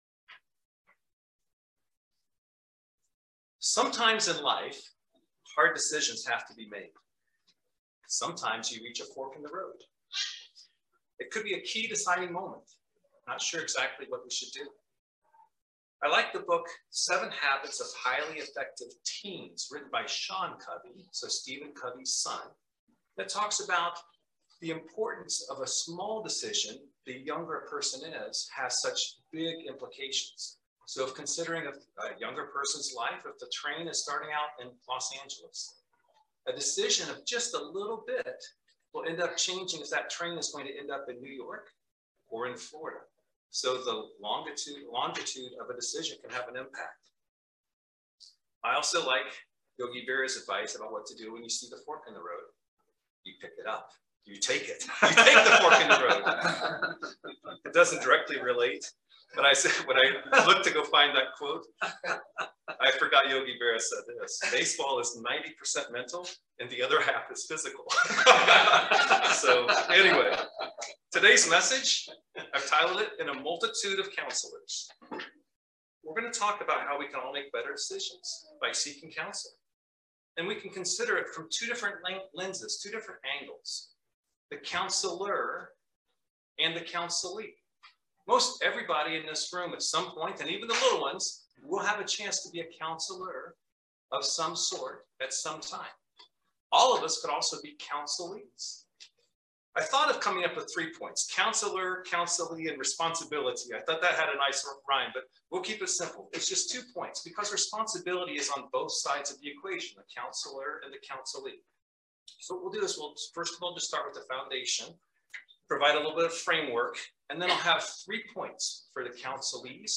This sermon talks about how we can all make better decisions by seeking counseling.